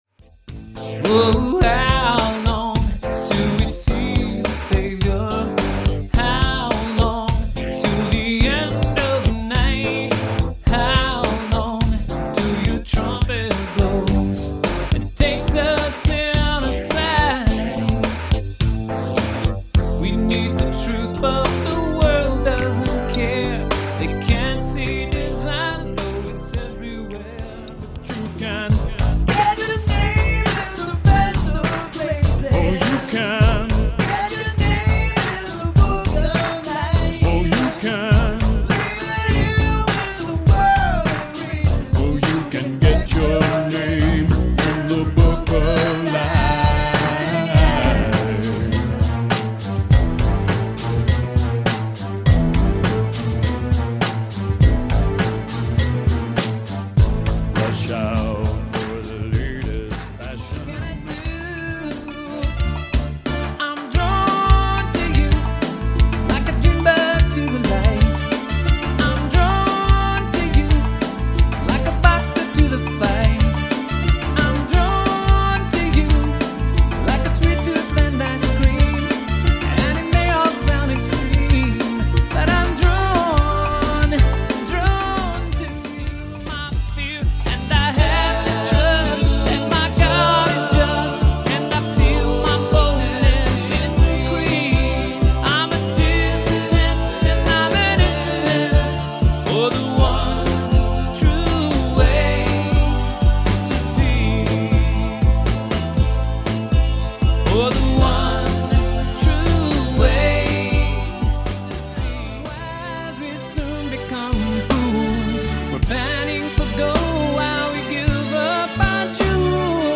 2nd CD of Christian-inspired music…